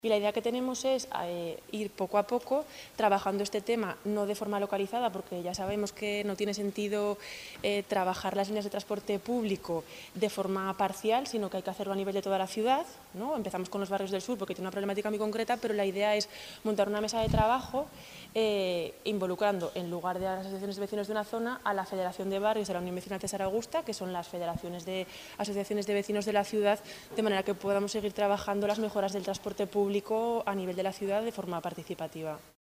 Escuche aquí declaraciones de la Concejala de Medio Ambiente y Movilidad, Teresa Artigas: